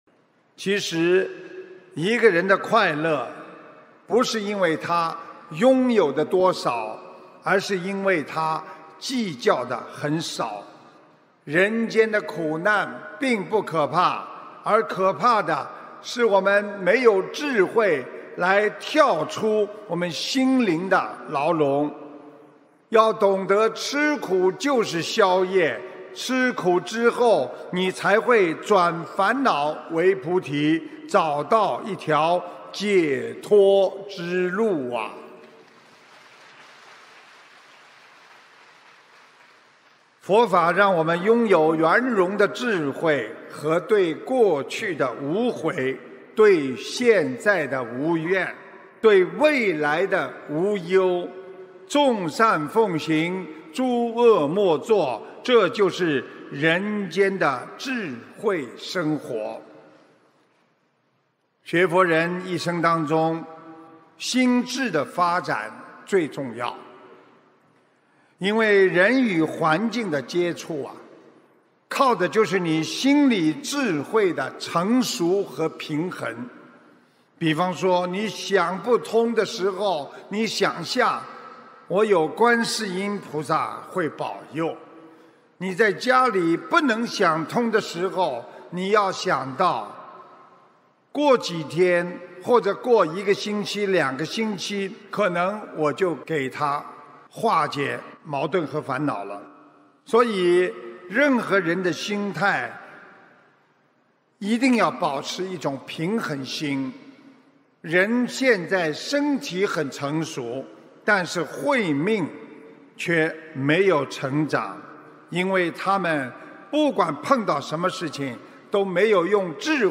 视频：132.任何人都要保持一种平衡的心态！马来西亚吉隆坡2019年10月18日 - 法会节选 心灵净土